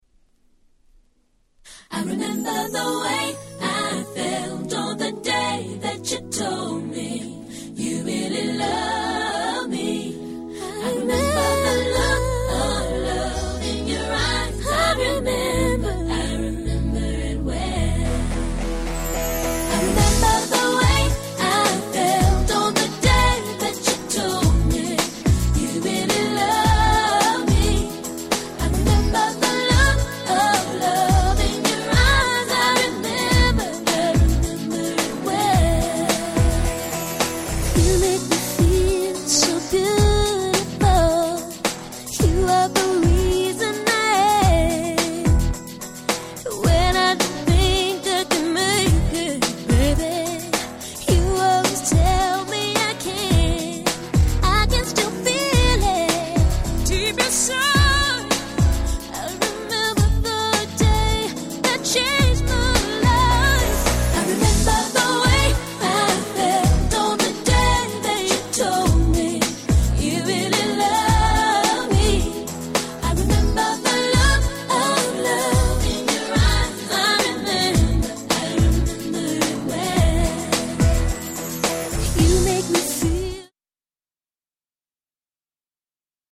White Press Only Nice R&B Complilation !!!!!